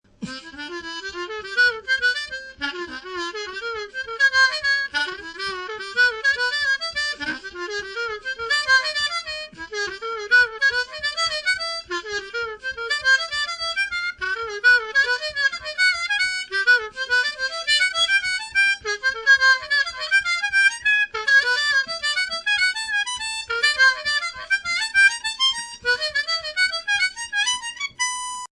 ちなみにこのモデル一本で全Keyを表現したのがこれです。